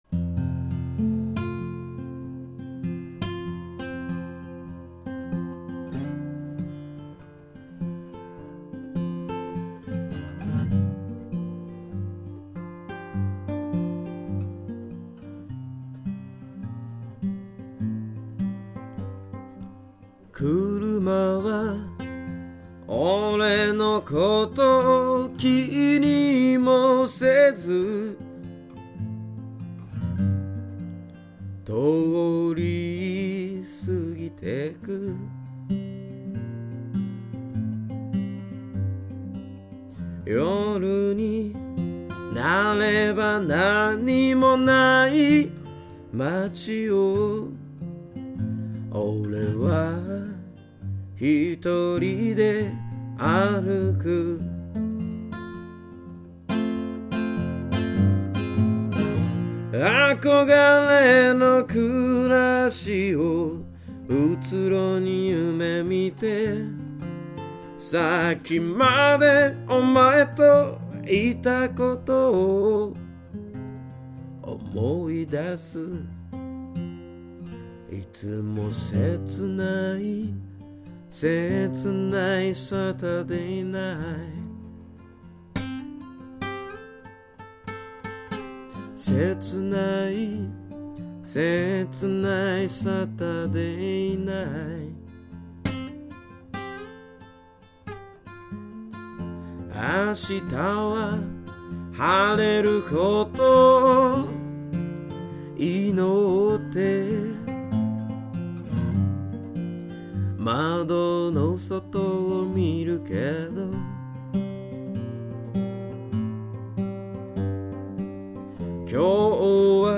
札幌きっての若きブルースマン。